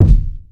CDK - BY Kick1.wav